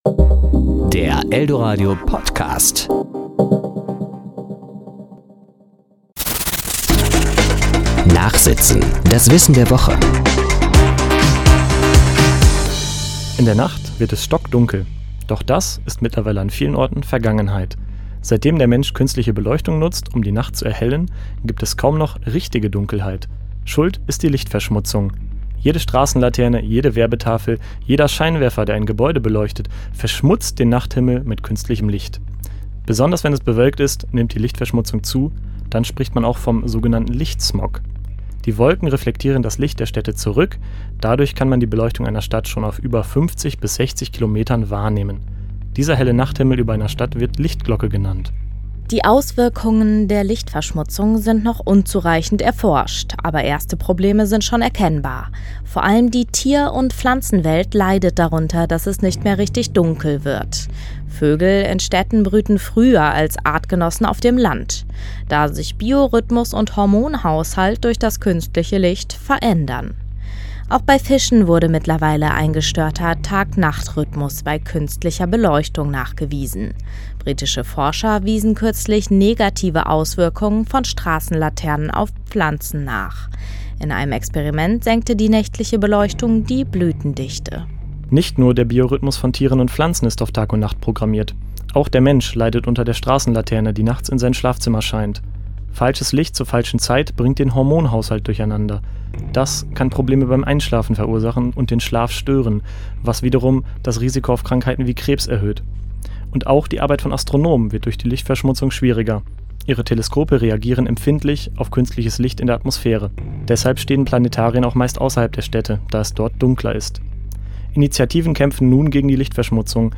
Serie: Beiträge